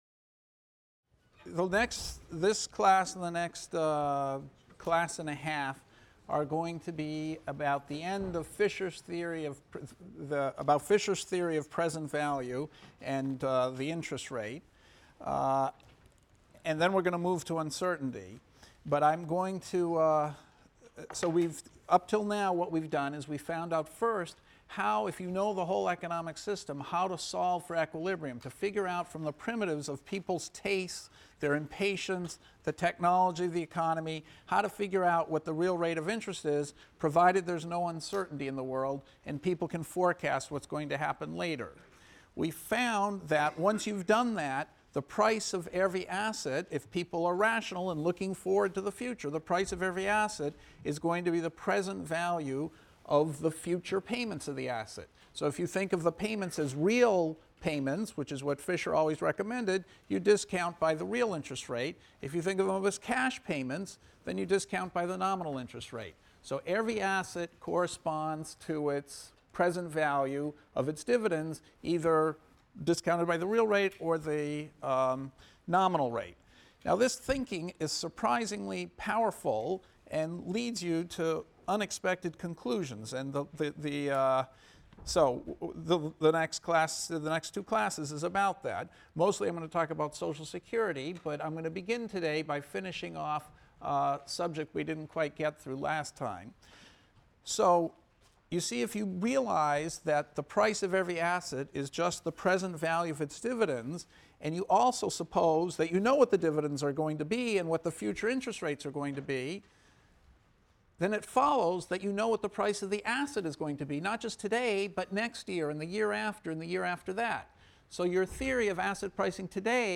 ECON 251 - Lecture 10 - Dynamic Present Value | Open Yale Courses